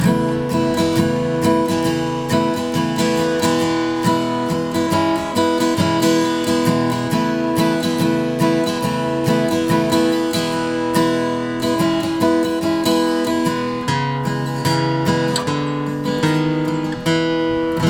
Open E tuning
Slow Strum Pattern
Strum_pattern.mp3